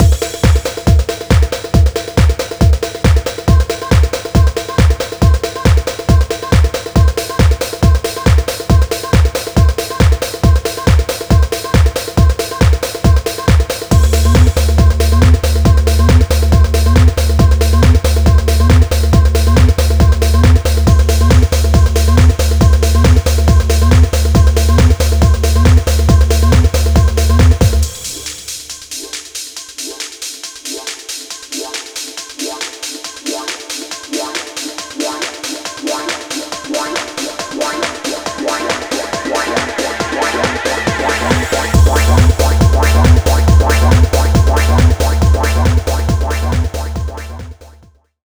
Kit 4 Mix.wav